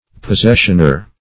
Possessioner \Pos*ses"sion*er\, n.